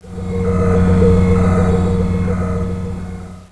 CATHEDRL.WAV